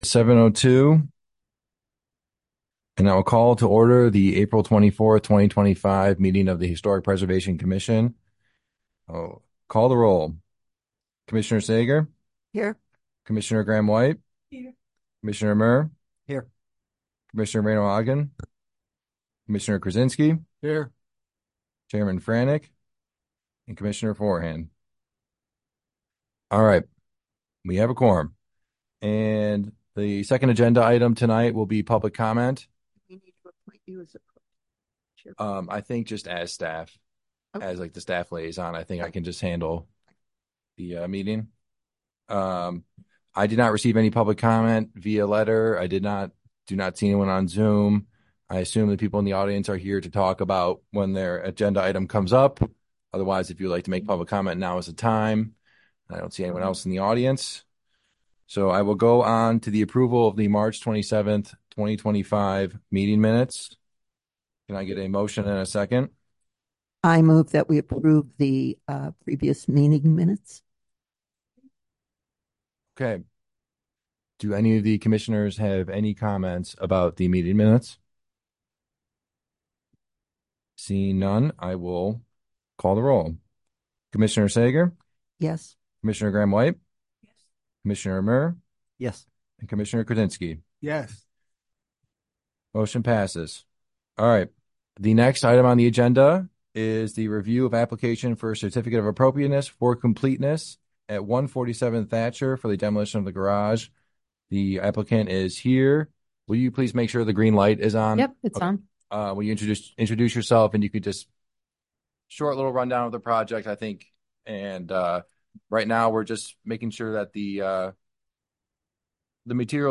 Special Meeting of the Historic Preservation Commission